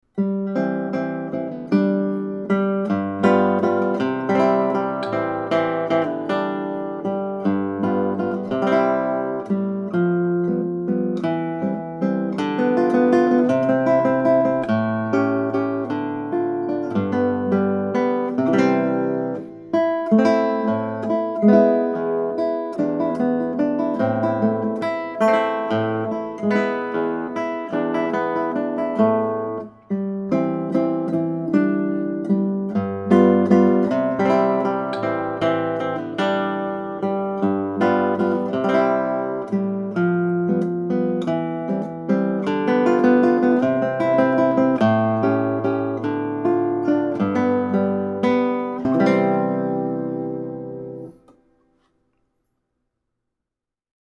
Next up, a petite waltz by Matteo Carcassi.
The waltz is a dance in triple meter, born as a folk dance in rural 18th-century Germany and Austria.
guitar